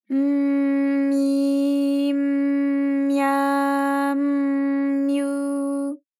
ALYS-DB-001-JPN - First Japanese UTAU vocal library of ALYS.
my_m_myi_m_mya_m_myu.wav